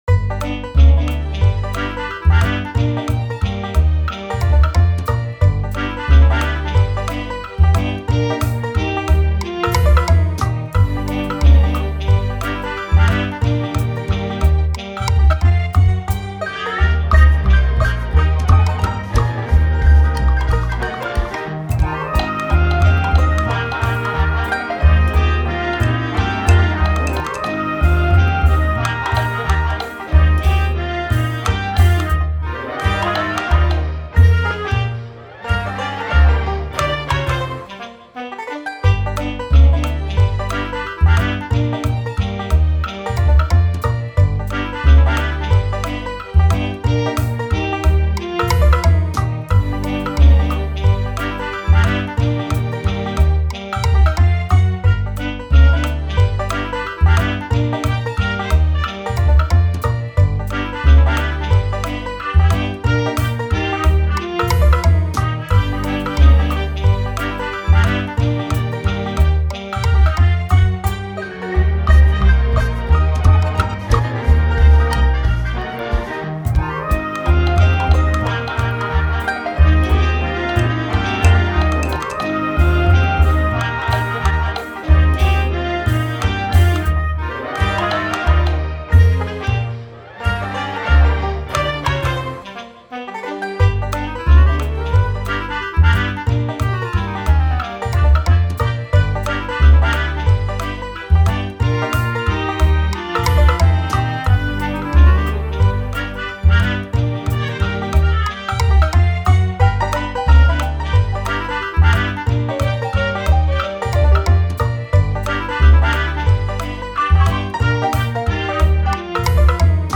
banda sonora banjo